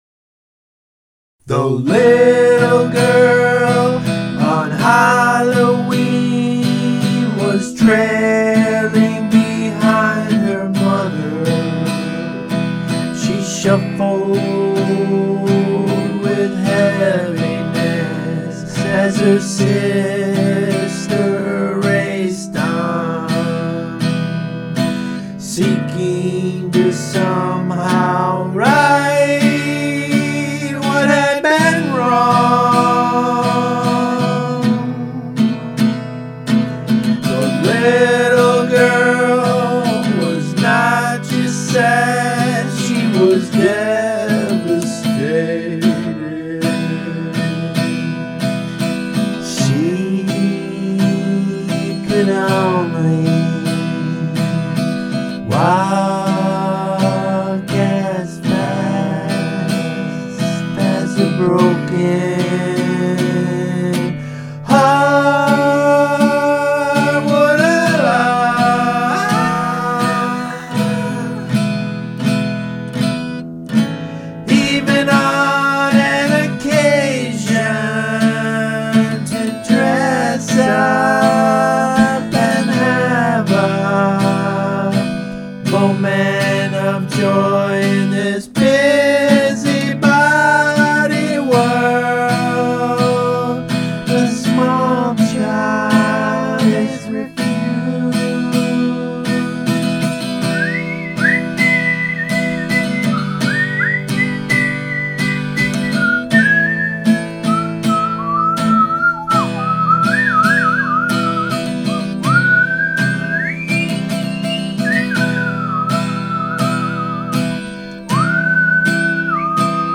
jazz, classical, rock